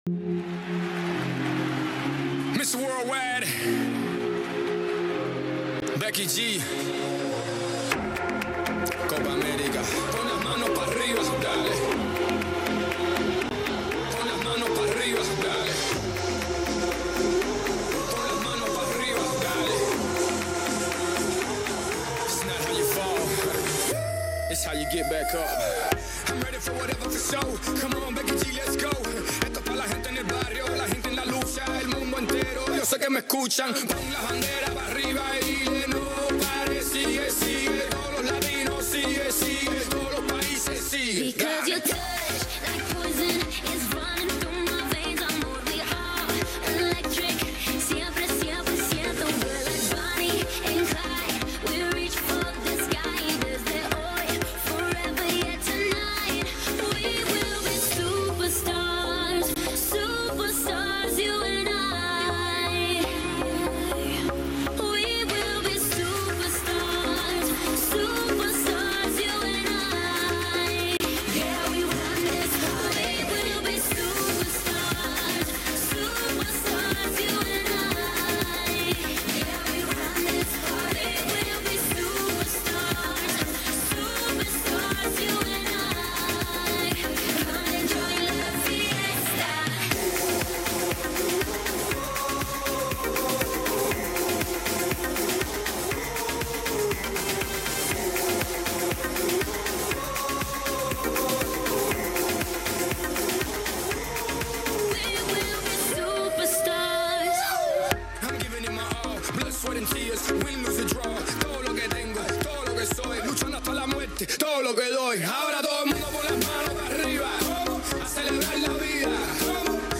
Listen to the fun and festive Spanglish track above!